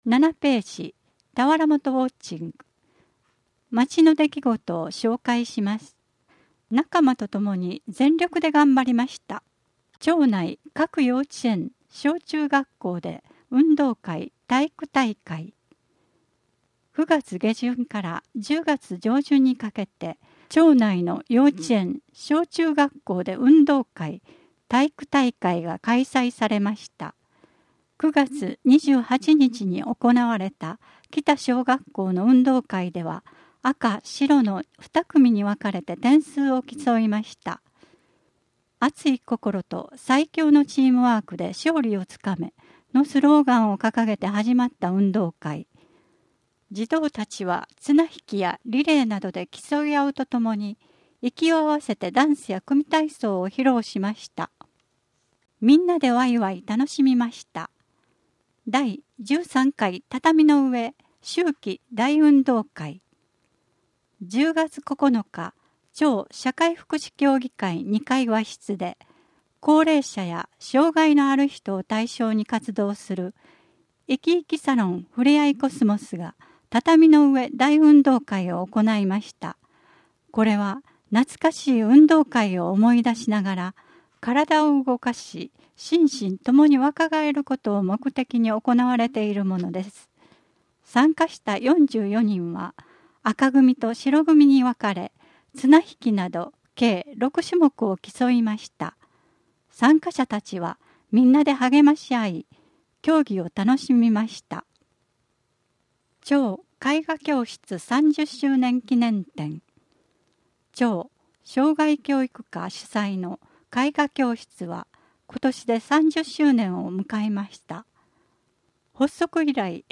音訳広報たわらもと7ページ (音声ファイル: 1.3MB)